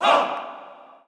Tm8_Chant57.WAV